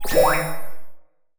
sci-fi_power_up_05.wav